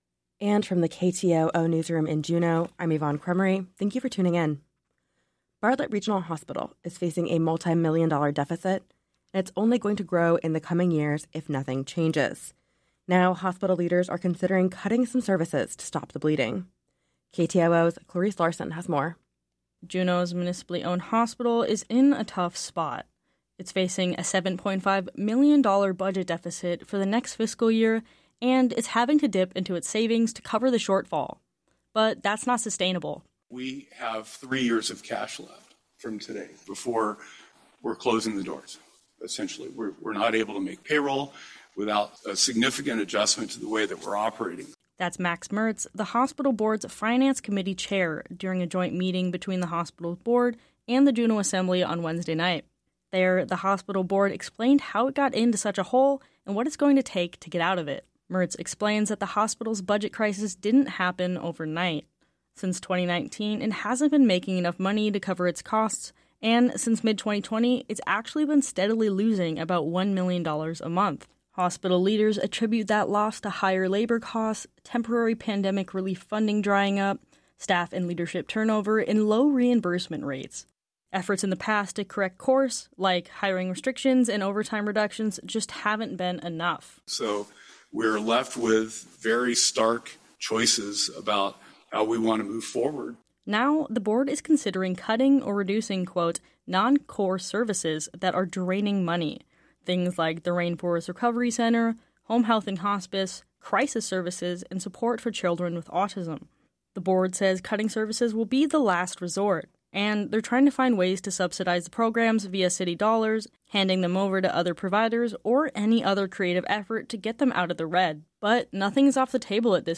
Newscast – Thursday, May 30, 2024